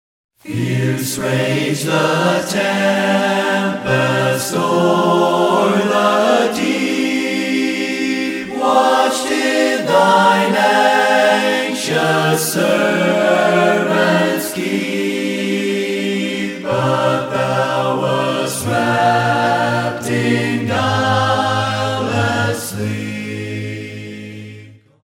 singing 16 hymns a cappella.